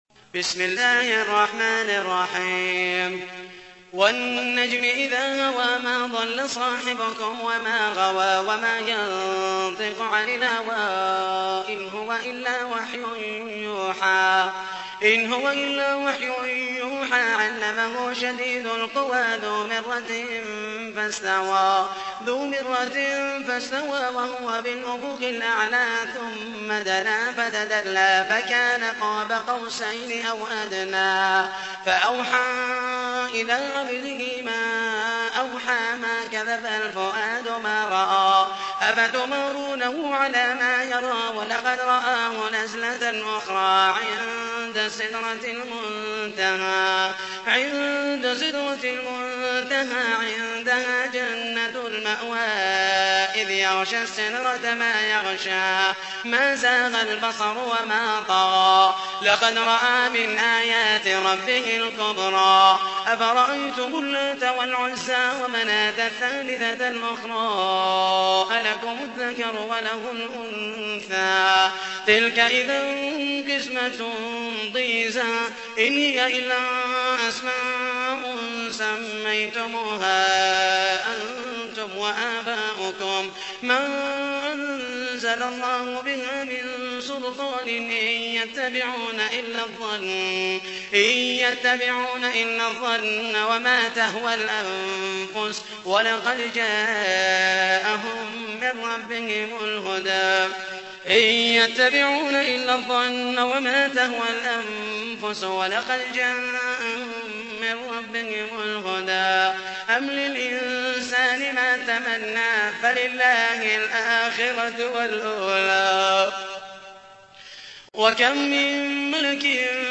تحميل : 53. سورة النجم / القارئ محمد المحيسني / القرآن الكريم / موقع يا حسين